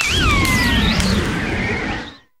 Cri de Virovent dans Pokémon HOME.